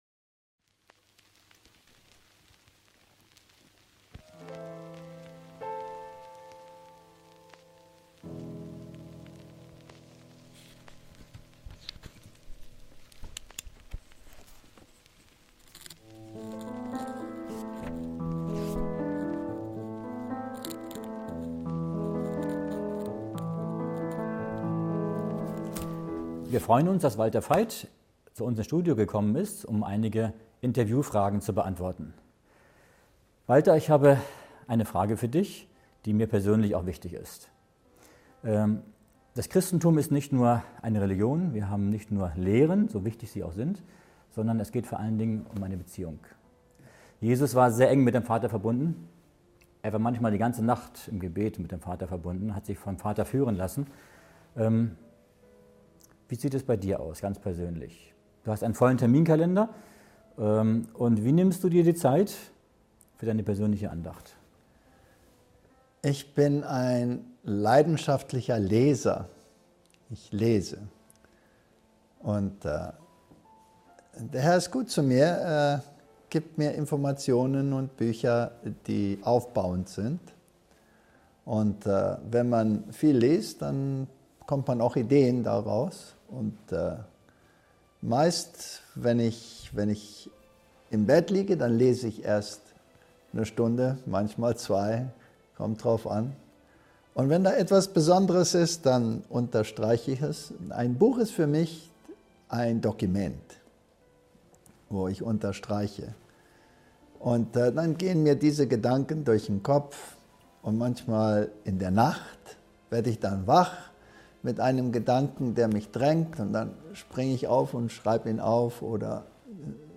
Im persönlichen Gespräch